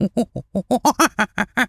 monkey_chatter_01.wav